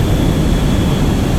jet.ogg